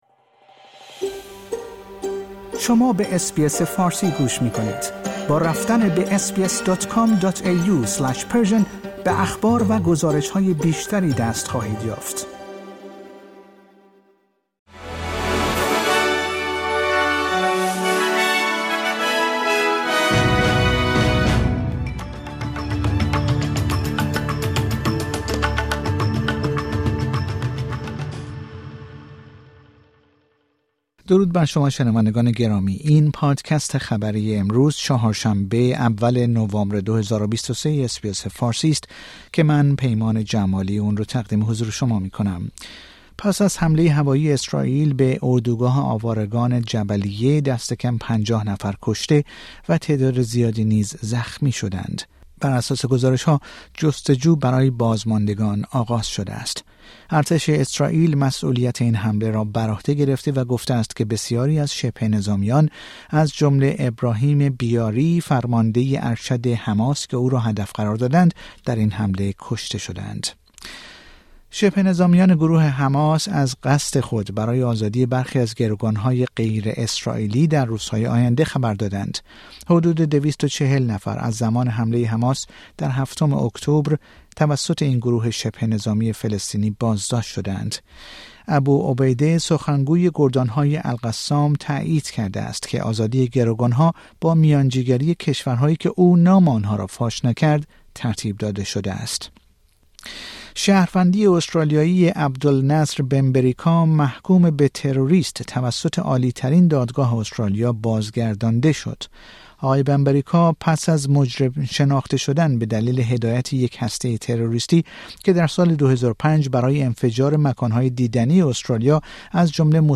در این پادکست خبری مهمترین اخبار استرالیا و جهان در روز سه شنبه اول نوامبر ۲۰۲۳ ارائه شده است.